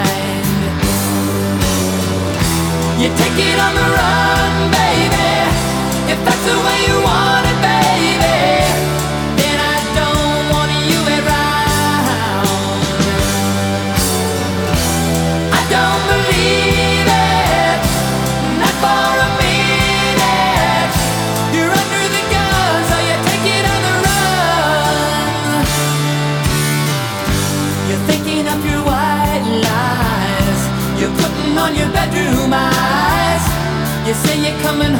Жанр: Поп музыка / Рок